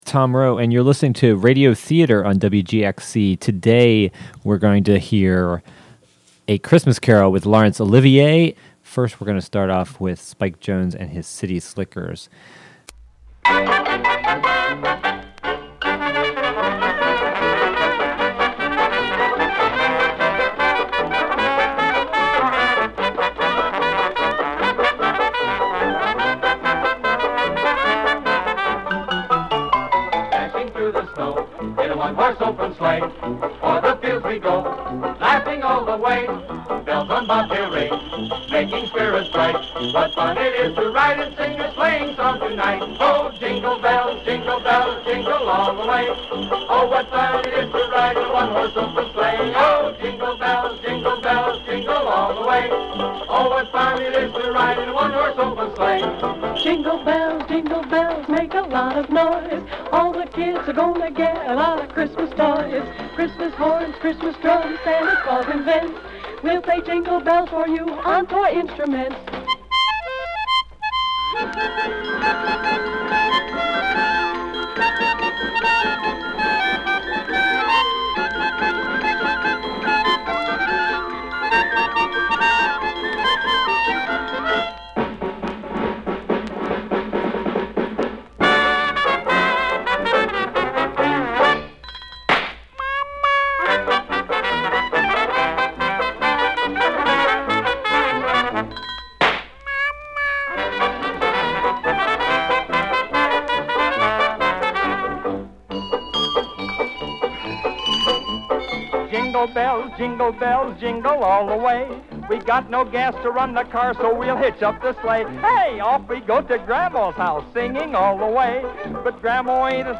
Laurence Olivier plays Scrooge in this radio theat...